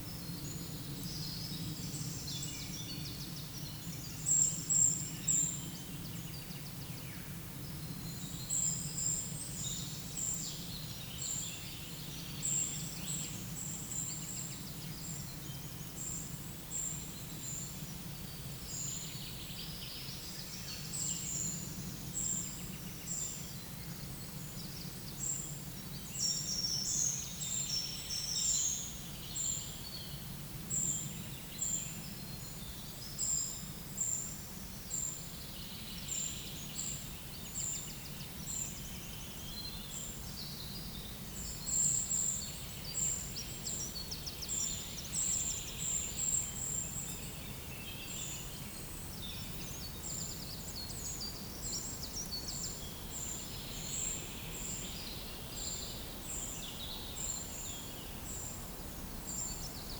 Monitor PAM
Certhia brachydactyla
Certhia familiaris
Troglodytes troglodytes
Leiopicus medius
Regulus ignicapilla